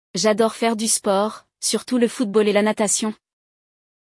Hoje, você vai ouvir um diálogo entre duas pessoas falando sobre os esportes que praticam e aprenderá como se expressar sobre esse tema em francês.